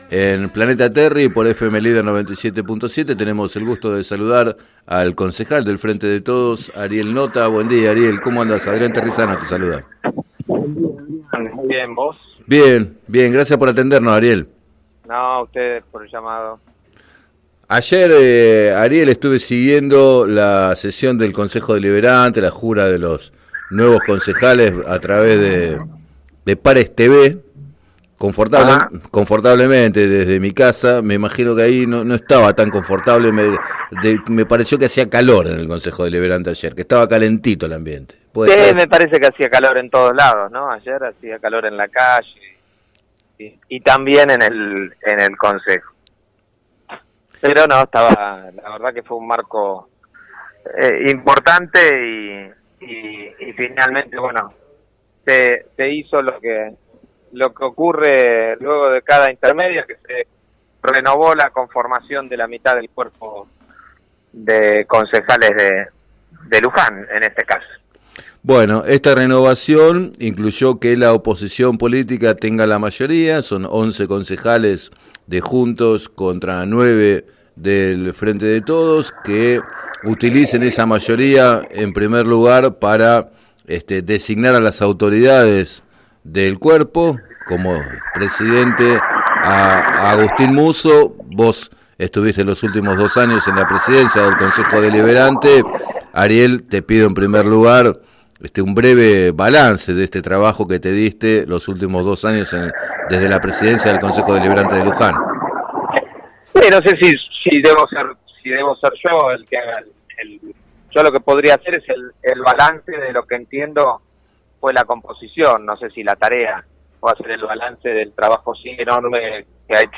En declaraciones al programa Planeta Terri de FM Líder 97.7, Notta señaló también,en este sentido, que los concejales opositores pretendieron resolver la discusión llamando por los medios a dialogar al intendente, en lugar de debatir el tema con sus pares.